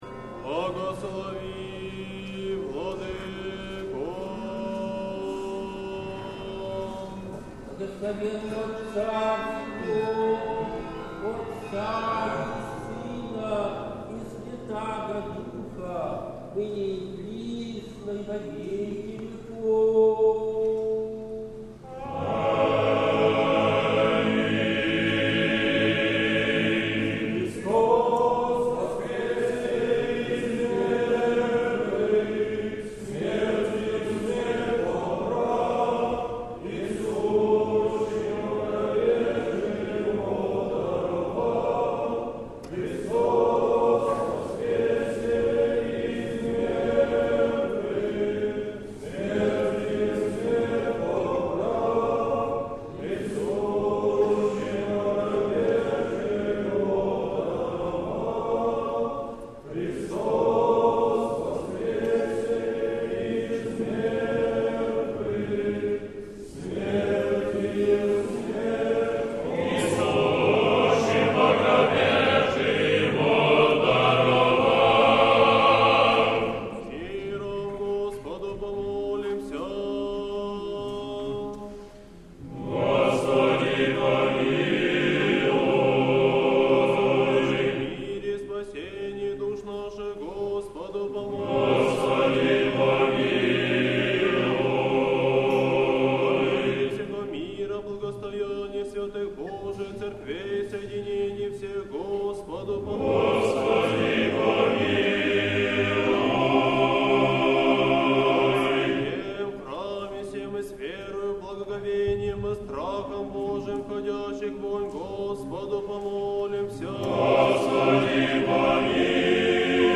Сретенский монастырь. Божественная литургия. Хор Сретенского монастыря.
Божественная литургия в Сретенском монастыре в Неделю 3-ю по Пасхе, святых жен-мироносиц